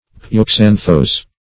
Search Result for " pyoxanthose" : The Collaborative International Dictionary of English v.0.48: Pyoxanthose \Py`o*xan"those\, n. [Gr.